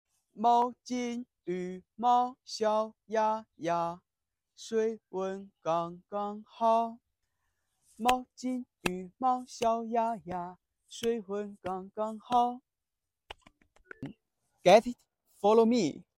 dancing song